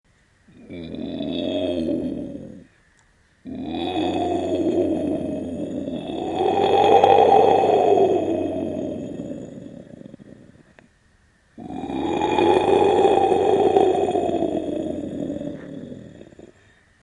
Tiger Growl Bouton sonore